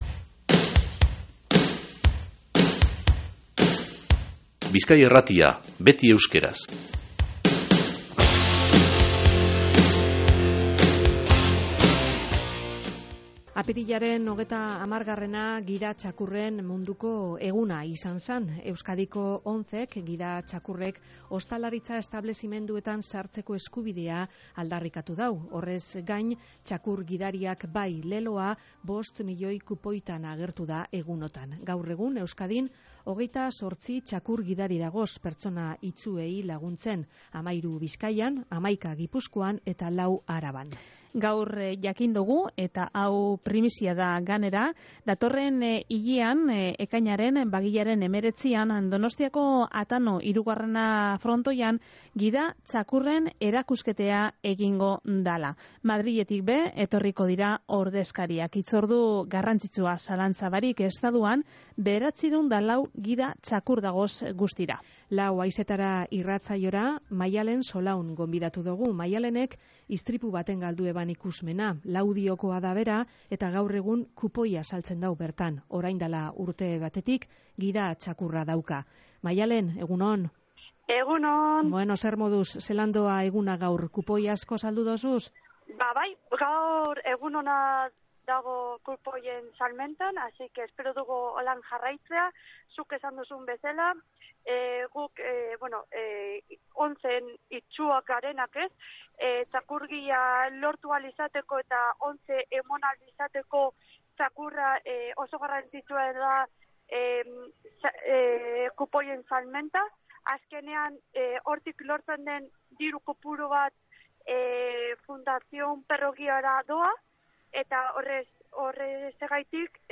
Lau Haizetara irratsaioan